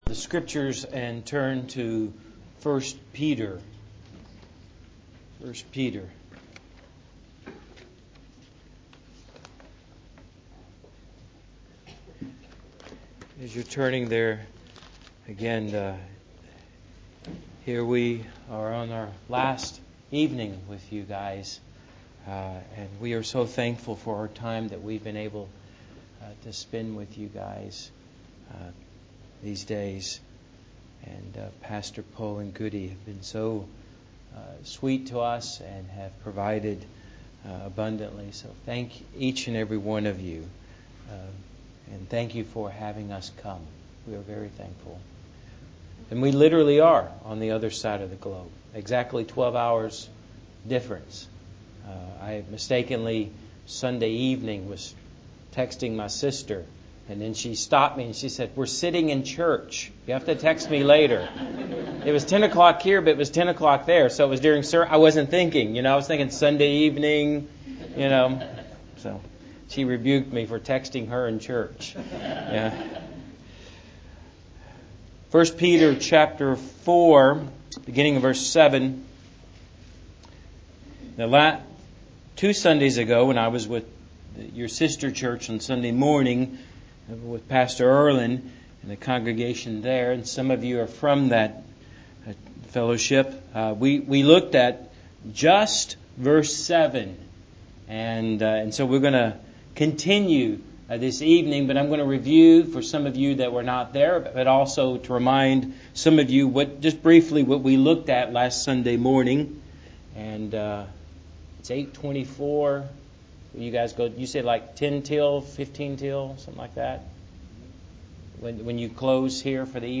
Preached on the 14th of September 2016.